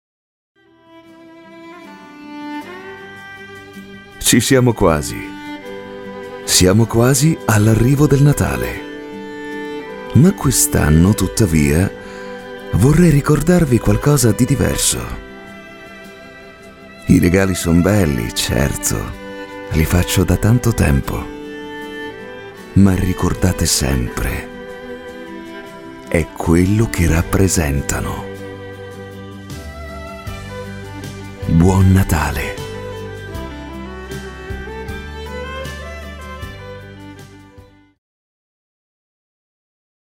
Commerciale, Chaude, Douce, Corporative, Profonde
Commercial